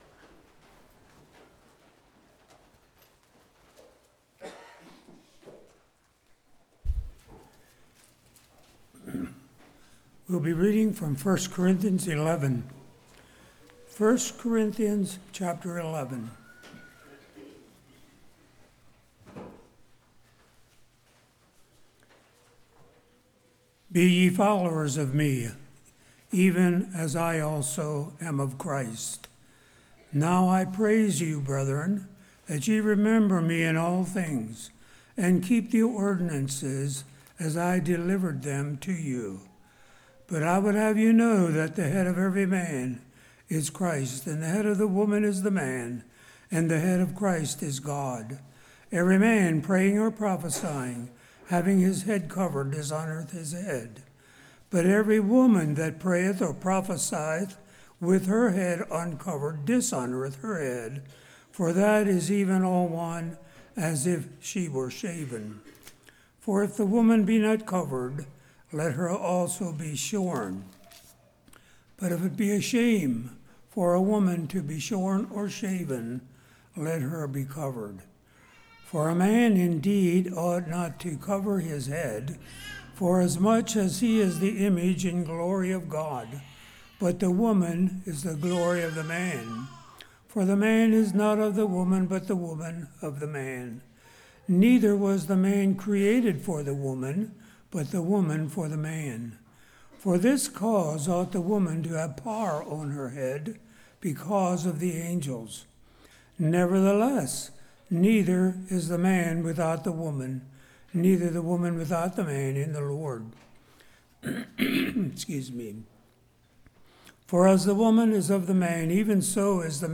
Series: Fall Lovefeast 2017
Service Type: Morning